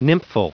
Prononciation du mot nymphal en anglais (fichier audio)
Prononciation du mot : nymphal